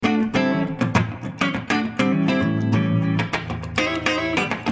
Funk guitars soundbank 1
Guitare loop - funk 28